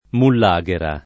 [ mull #g era ]